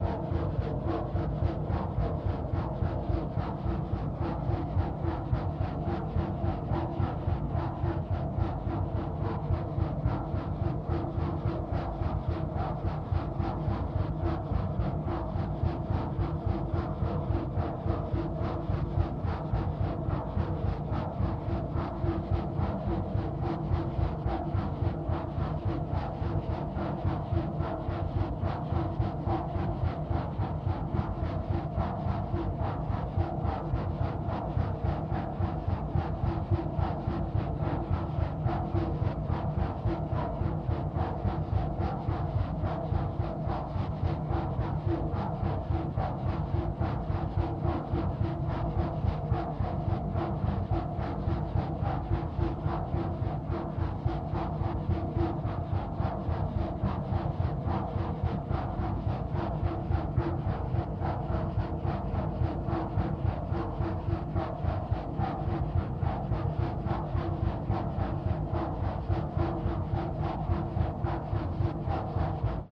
Ambiance, Machine Pulse; Rhythmic Three Beat Steamy Mechanical Pulse Medium Cu